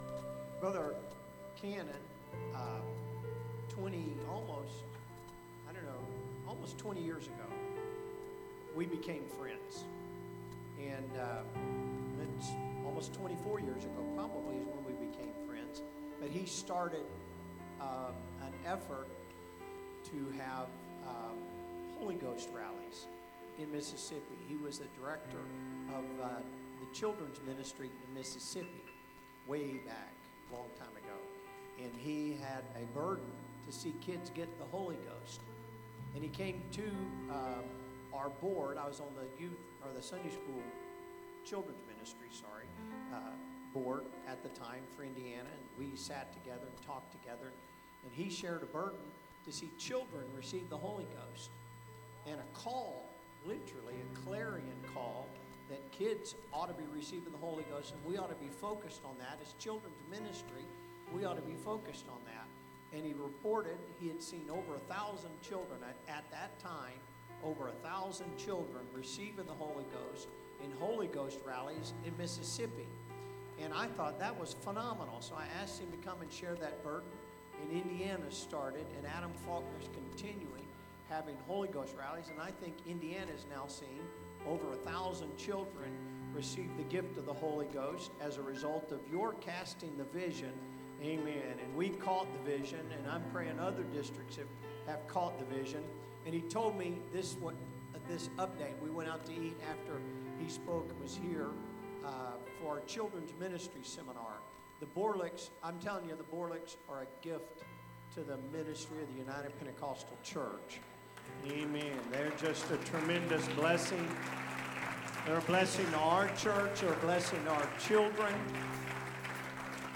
Sermons | Elkhart Life Church